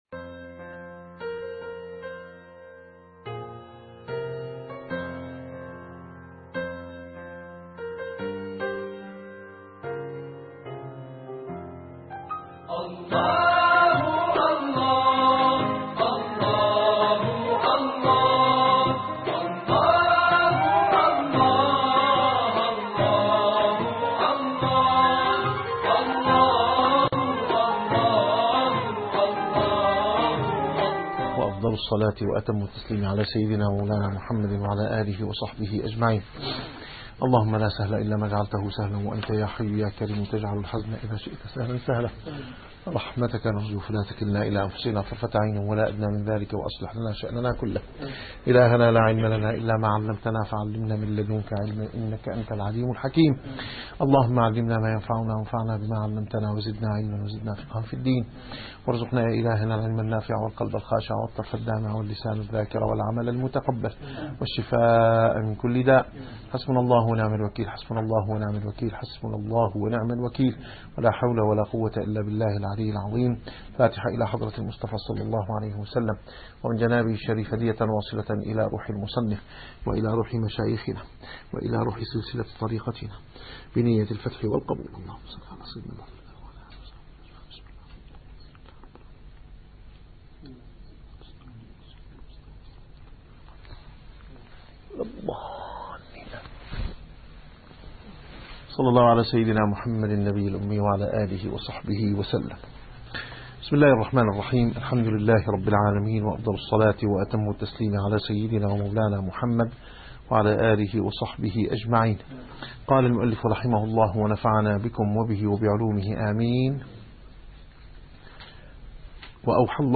- الدروس العلمية - الرسالة القشيرية - الرسالة القشيرية / الدرس الخامس والستون.